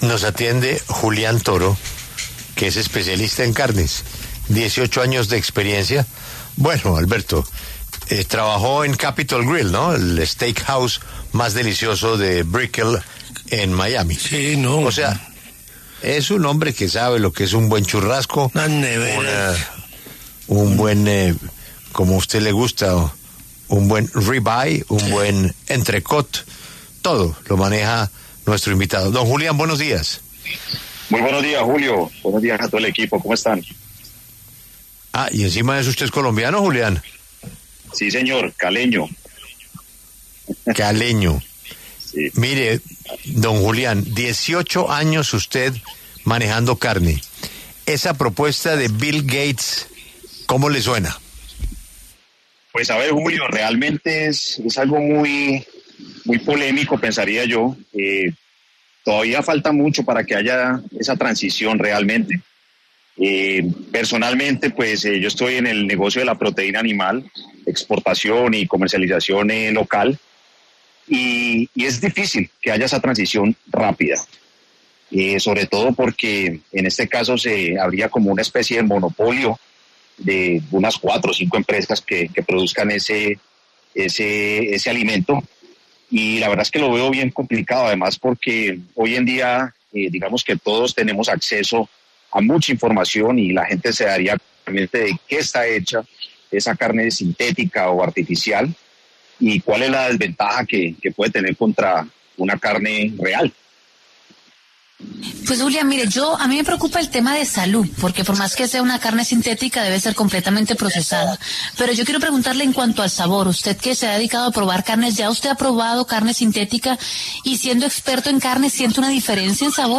La W habló con un especialista en proteína animal, quien mencionó los pros y los contras de la carne sintética.